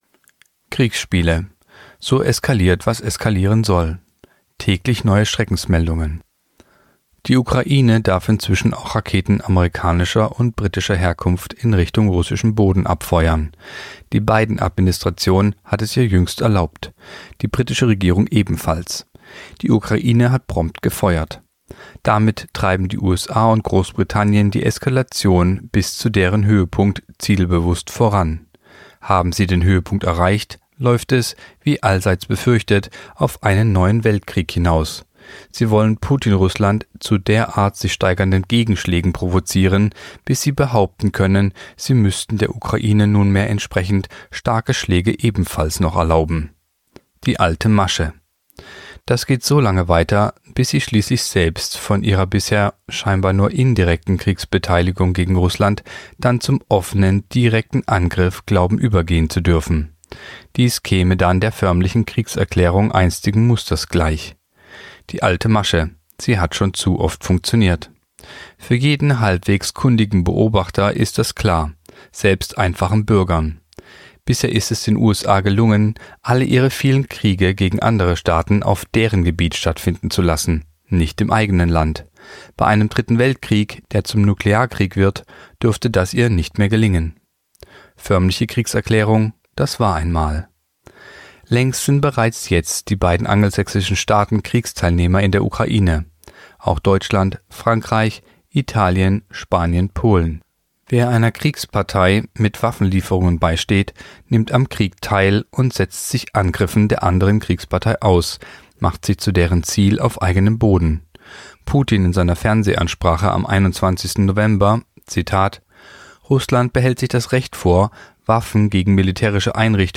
Der erfolgreiche Artikel „So eskaliert, was eskalieren soll“ von Klaus Peter Krause, vertont